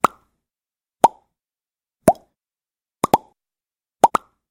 Звуки чпок
Звук щелчка ртом